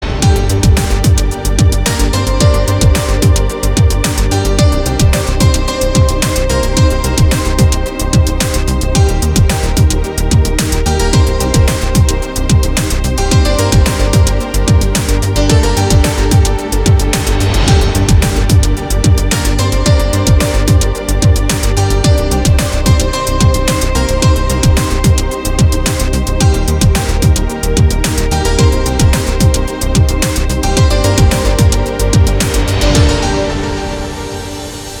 Рингтоны New Age